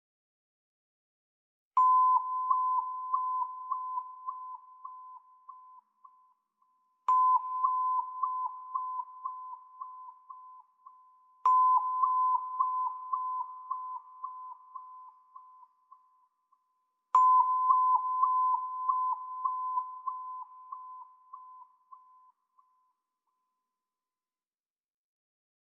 Meinl Sonic Energy Wah-Wah Tube C6, 432 Hz, Champagnergold (WWTC6)
Gestimmt auf 432 Hz, erzeugen die Röhren einen harmonischen und beruhigenden Klang, ideal für Klangheilung, Meditation oder musikalische Experimente.
Schlage die Röhre an und benutze deinen Daumen, um das Wah-Wah-Loch abwechselnd zu öffnen und zu schließen – so entsteht der charakteristische, fesselnde Sound dieser Röhren. Diese einfache, aber einzigartige Spieltechnik sorgt für ein dynamisches und ausdrucksstarkes Klangerlebnis.